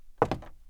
woodFootstep03.wav